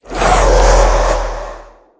minecraft / sounds / mob / wither / idle4.ogg